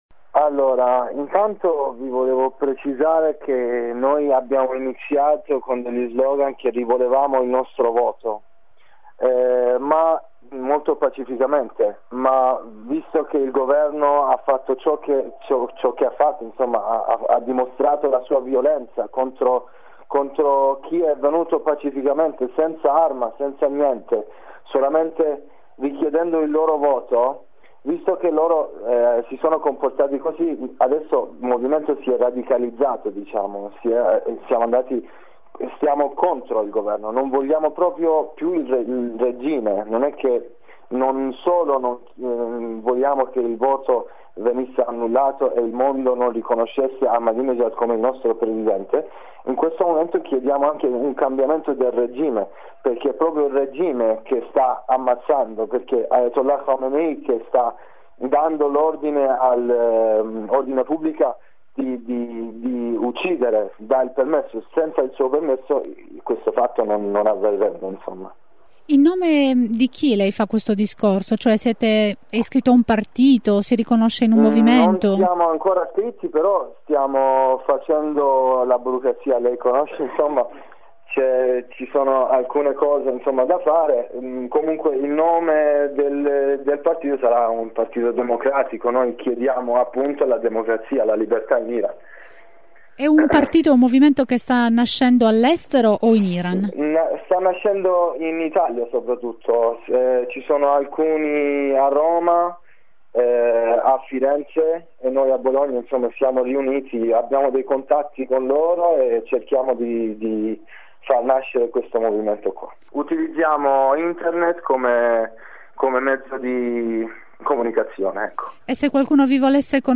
Ora contestano il regime tout court, come spiega uno studente che preferisce rimanere anonimo:
studente-iraniano.mp3